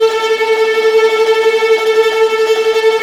Index of /90_sSampleCDs/Roland L-CD702/VOL-1/STR_Vlas Bow FX/STR_Vas Tremolo